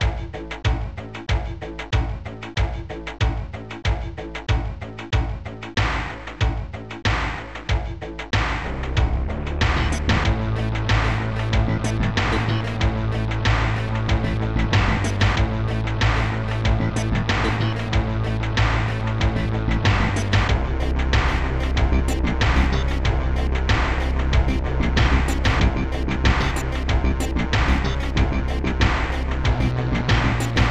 Protracker Module
ST-22:D-50-Lazerpiano ST-22:D-50-JarreTense ST-22:D-50-Slapbass ST-04:sanbornbass1 ST-34:eurythbass1 ST-34:eurythbass5 ST-34:eurythbass2